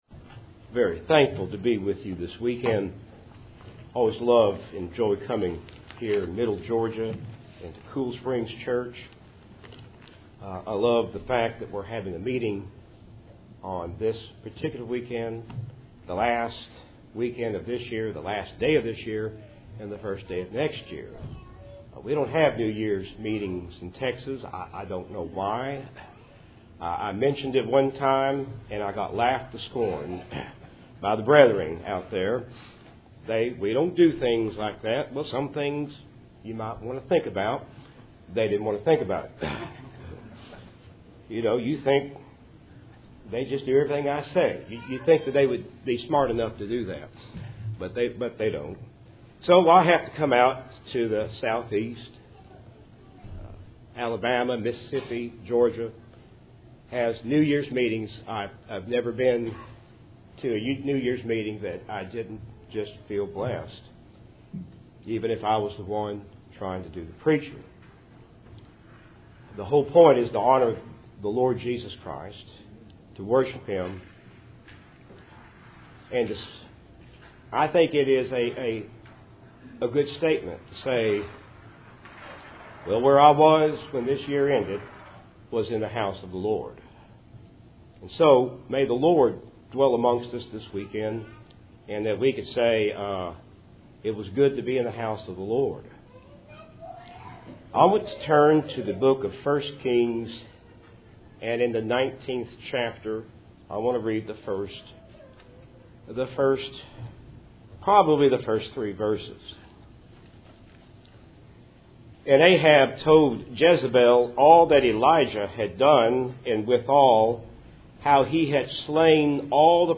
1 Kings 19:1-3 Service Type: Cool Springs PBC New Years Eve Meeting %todo_render% « John 1:29-38 Mount of Transfiguration